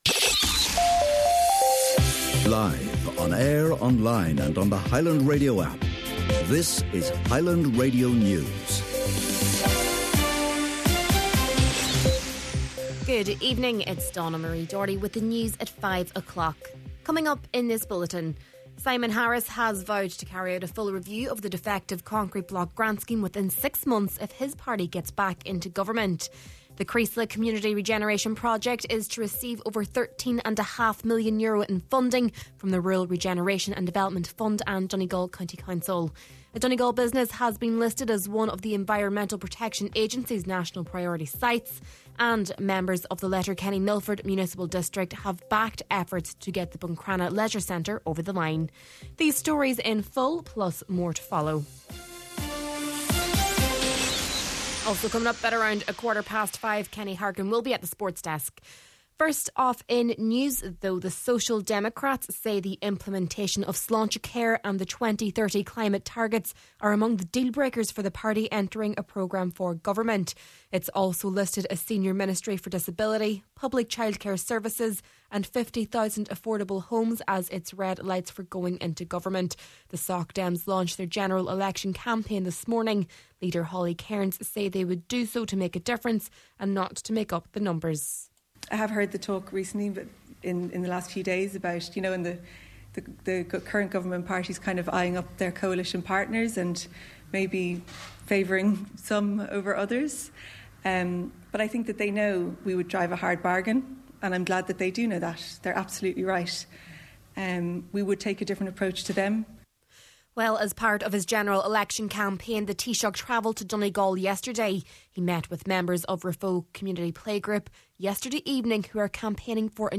Main Evening News, Sport and Obituary Notices – Tuesday, November 12th